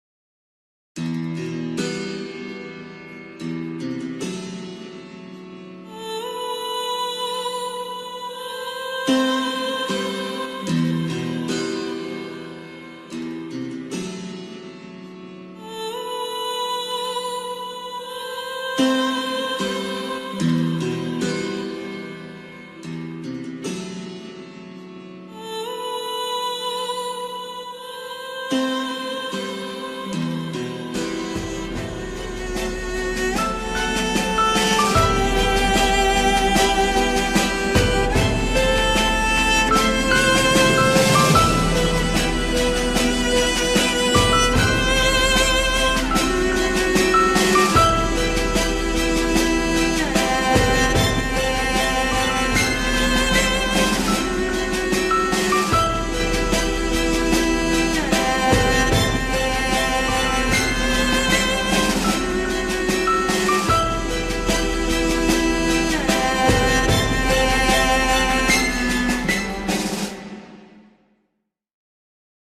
tema dizi müziği, duygusal hüzünlü gerilim fon müziği.